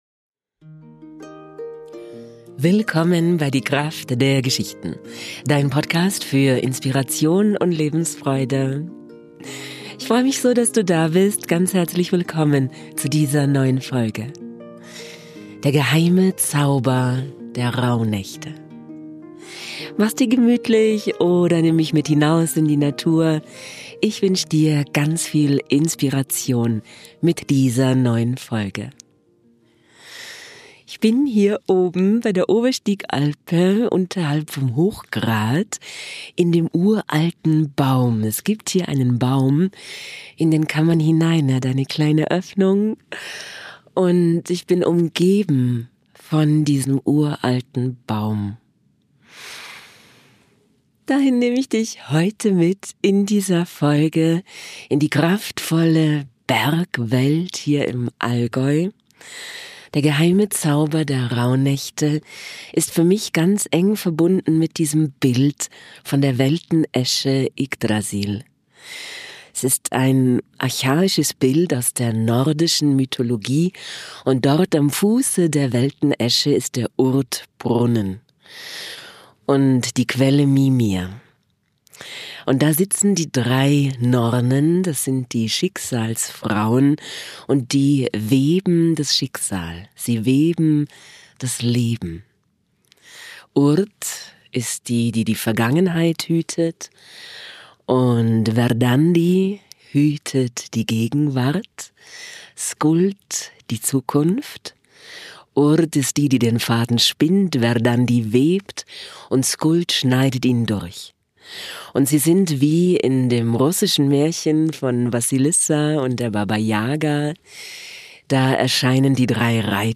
In dem uralten Bergahorn unterm Hochgrat hab ich diese neue Podcastfolge für Dich aufgenommen. Tauch mit mir ein in den mystischen Zauber der Rauhnächte.